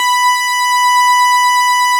Added synth instrument
snes_synth_071.wav